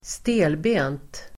Uttal: [²st'e:lbe:nt]